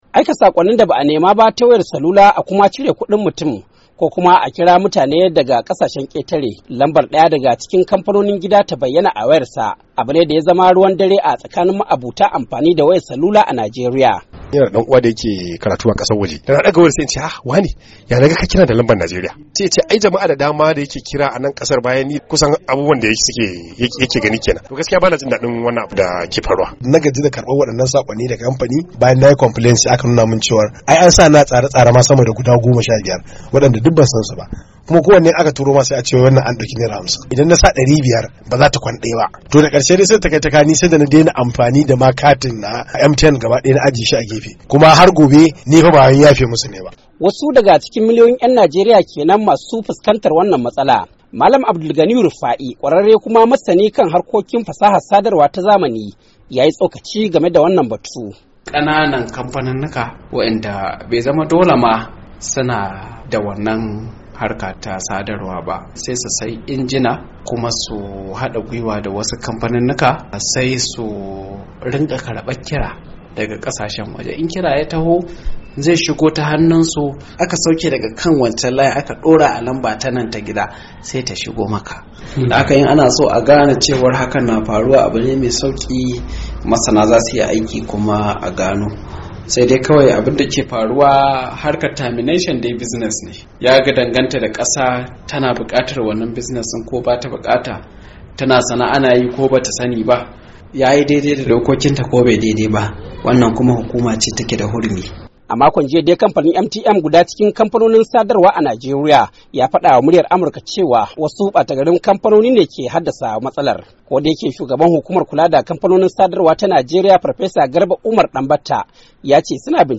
Labari da Dumi-Duminsa